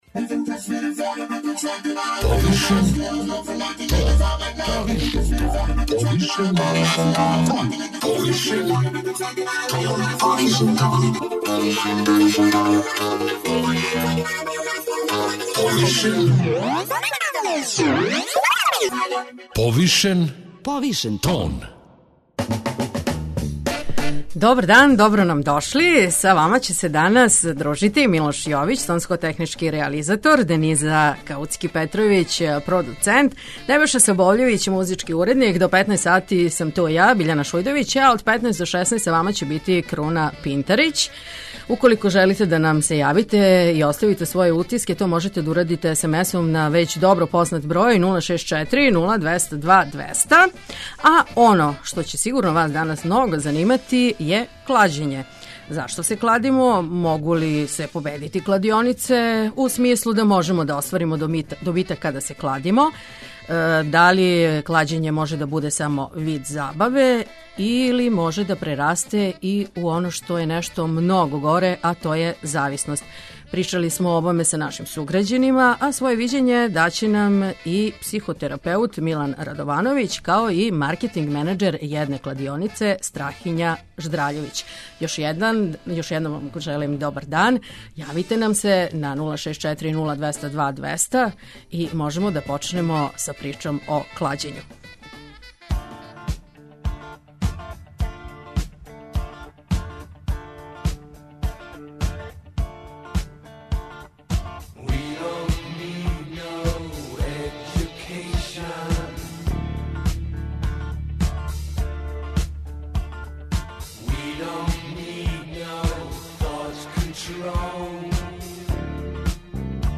Питали смо грађане да ли се кладе и да ли верују да је то добар начин стицања новца.